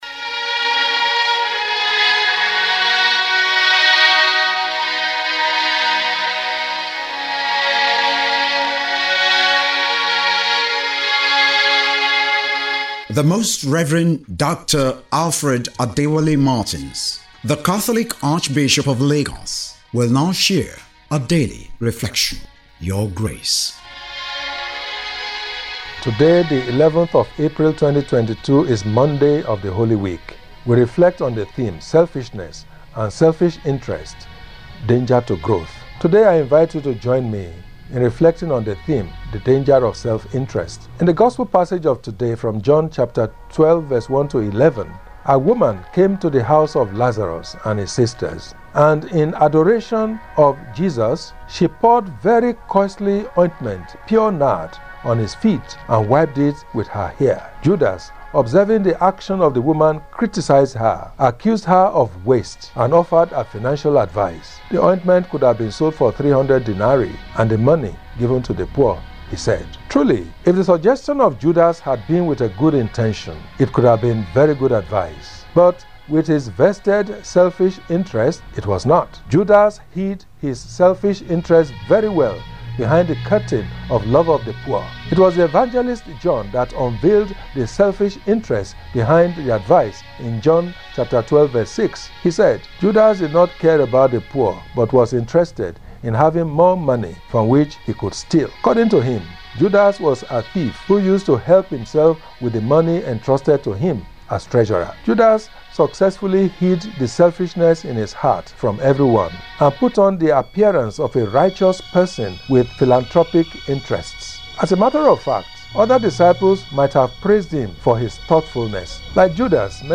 LENTEN REFLECTION WITH ARCHBISHOP ALFRED MARTINS.
LENTEN-TALK-MON-11.mp3